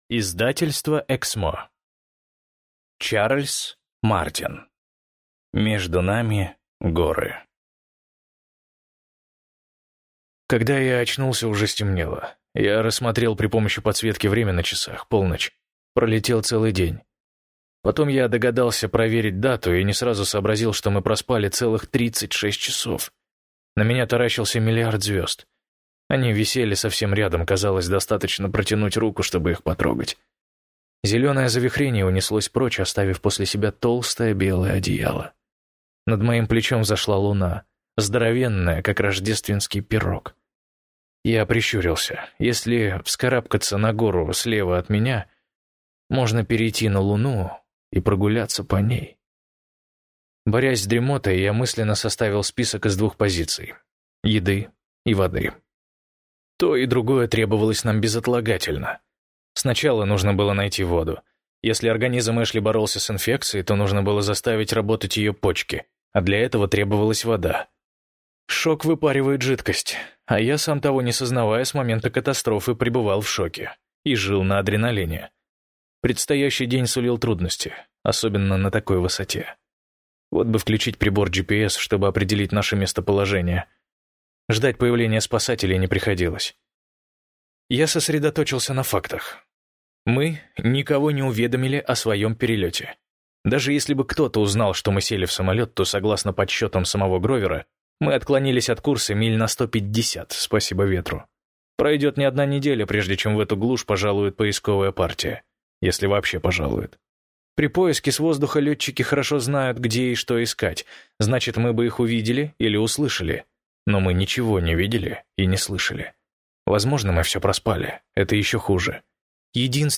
Аудиокнига Между нами горы - купить, скачать и слушать онлайн | КнигоПоиск